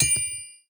hit_golden_crate_02.ogg